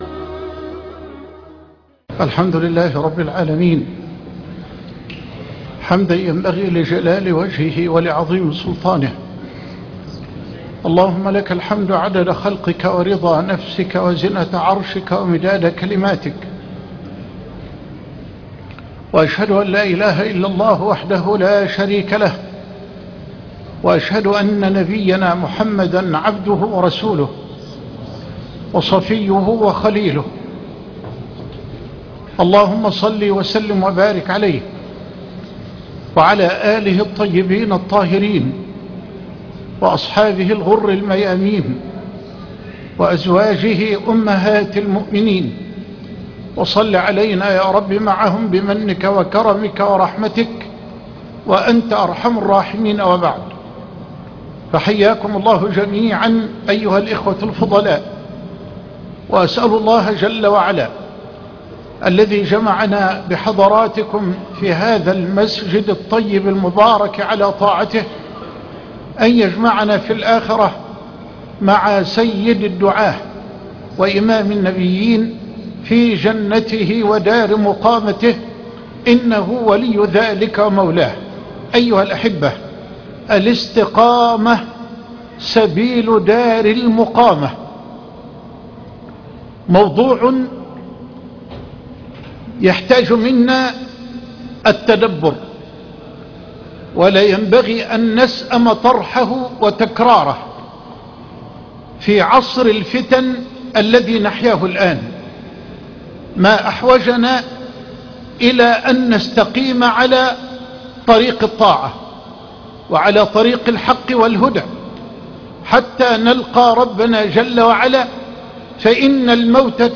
الاستقامة سبيل دار المقامة - درس للشيخ محد حسان بدولة الكويت - فضيلة الشيخ محمد حسان